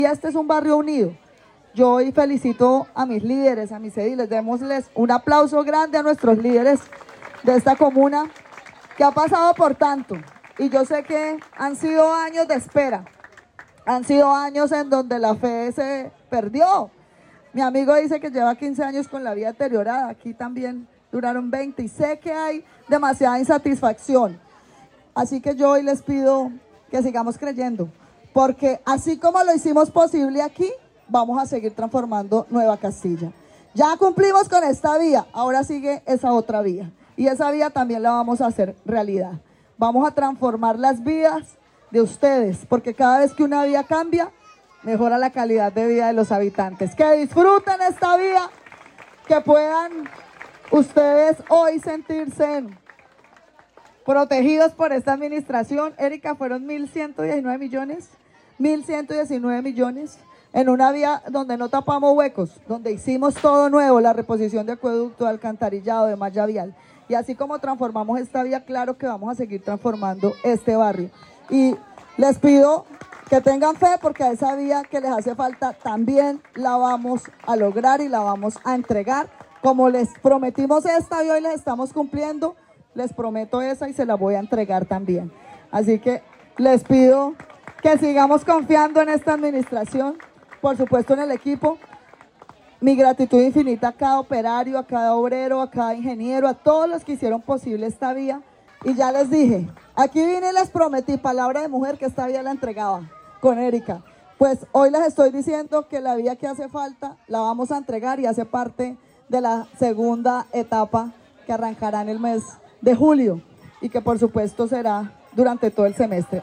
Disfruten todos esta obra”, expresó la alcaldesa Johana Aranda durante la entrega.